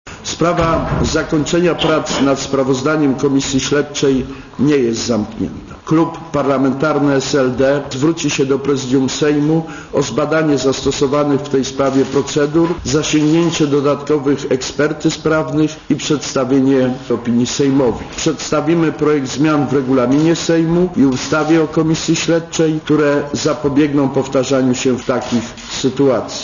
Mówi Krzysztof Janik
"Sprawa zakończenia prac nad sprawozdaniem Komisji Śledczej nie jest zamknięta" - powtórzył w sobotę na konferencji prasowej Janik.